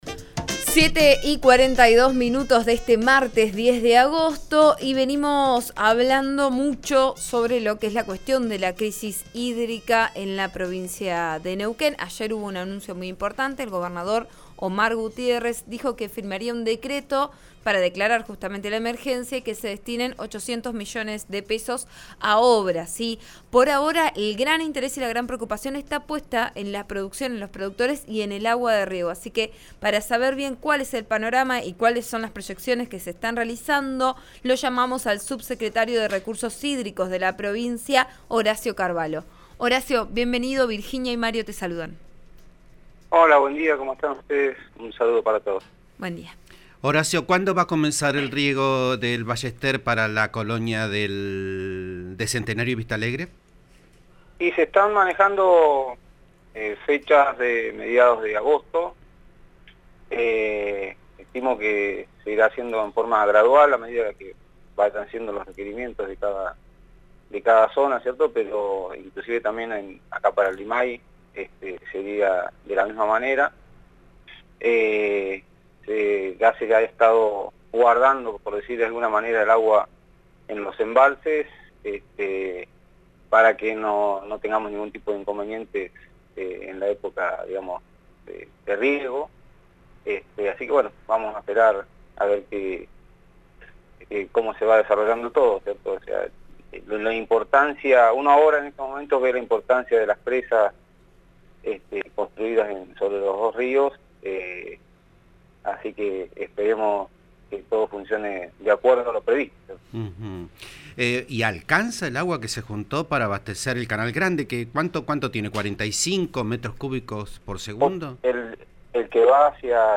Escuchá la entrevista al subsecretario de Recursos Hídricos, Horacio Carvalho, en RN RADIO: